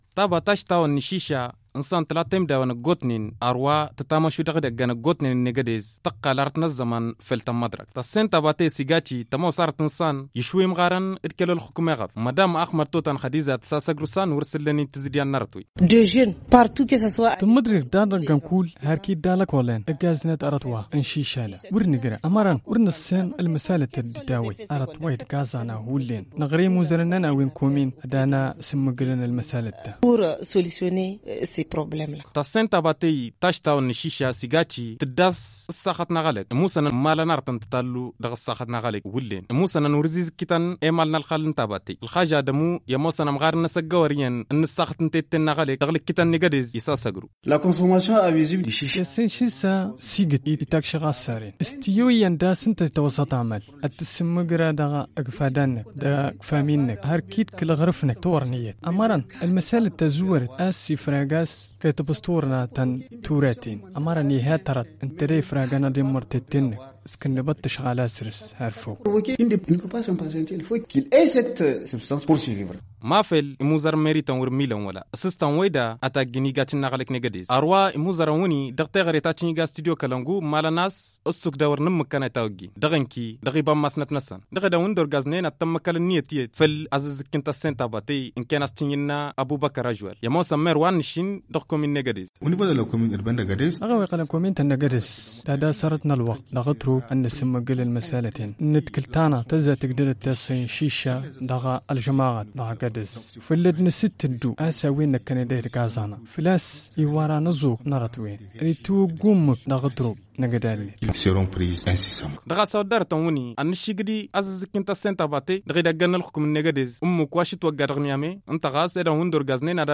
Voici son reportage.